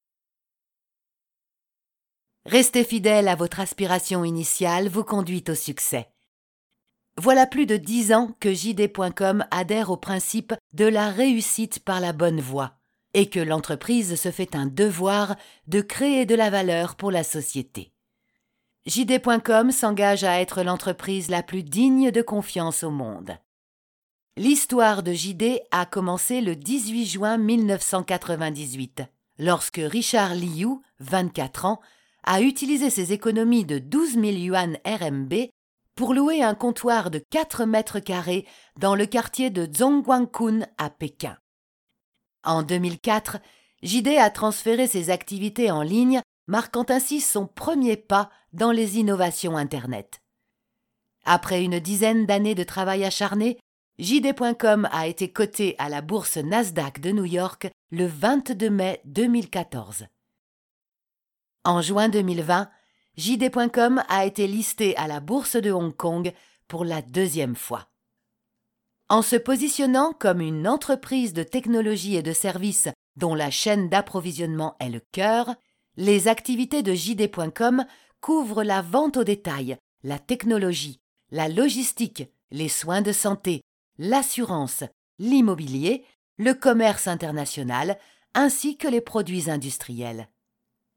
宣传片【成熟稳重】